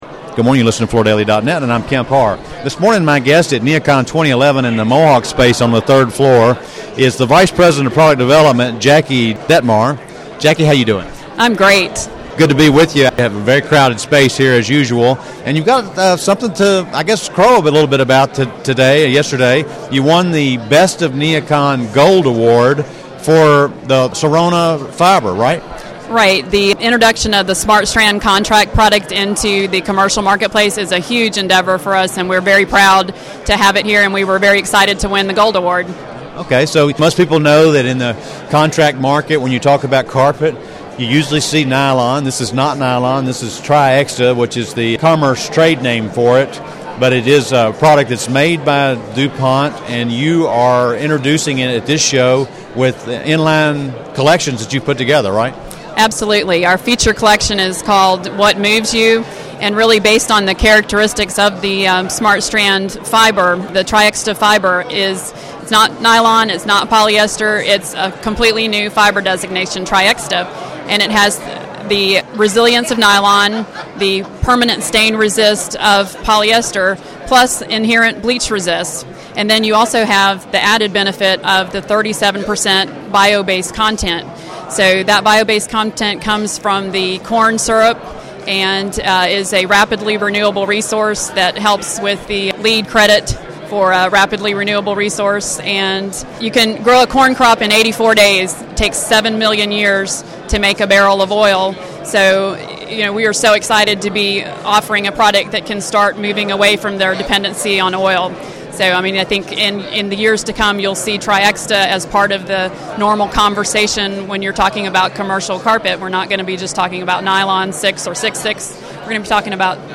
Listen to the interview to hear more about the "What Moves You" collection and about Mohawk's other key introductions at NeoCon 2011.